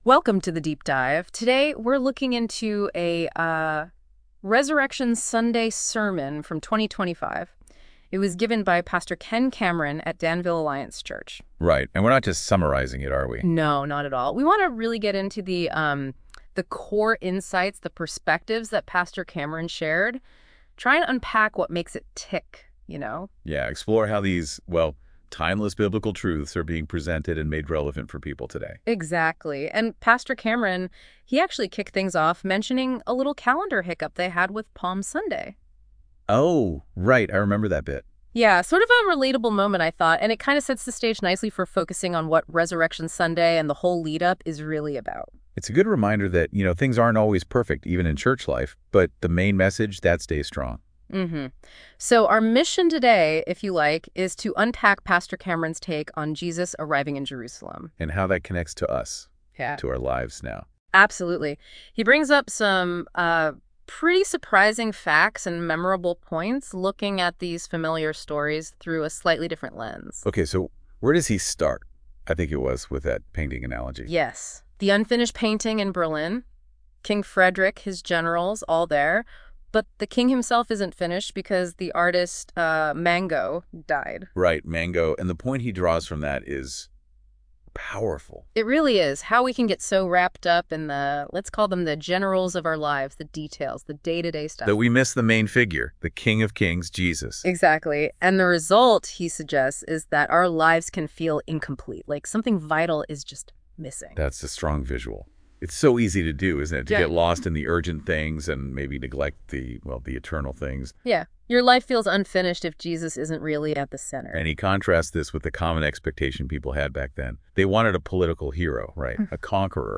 Apr 20, 2025 Acclamation, Crucifixion, Resurrection (Resurrection Sunday 2025) MP3 PDF SUBSCRIBE on iTunes(Podcast) Notes This audio source is a sermon delivered on Resurrection Sunday that reflects on the events of Holy Week in Jerusalem, focusing on the diverse responses to Jesus's arrival.